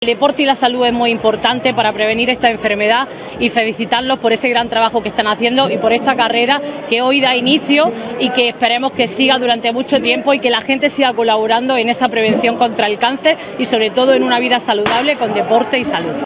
Matilde-Diaz-Diputada-Fomento-Agrario-Carrera-Contra-el-Cancer.wav